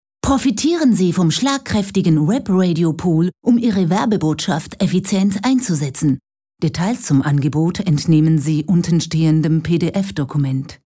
Voice Over
Spot Webradiopool